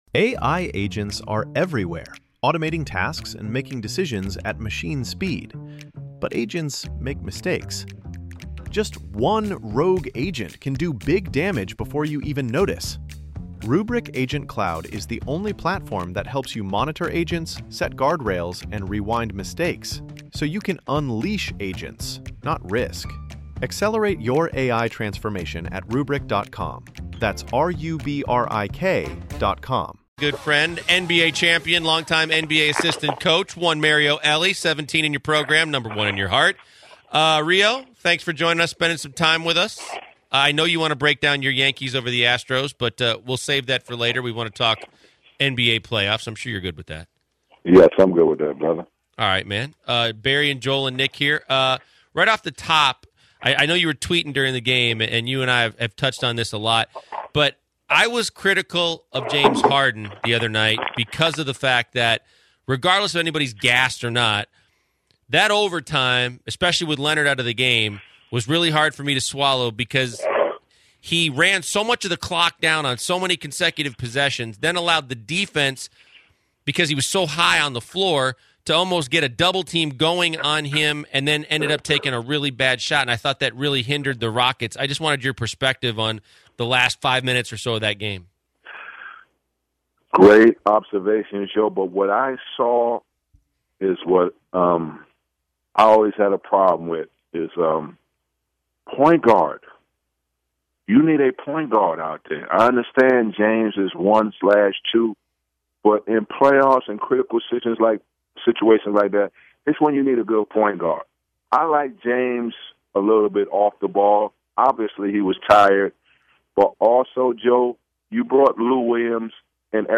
Mario Ellie Interview
Mario Ellie calls in to talk about the Houston Rockets and the pivotal game 6 against the San Antonio Spurs tonight, they talk about the key matchups and if Kwaii Lenoard will be able to play tonight or not.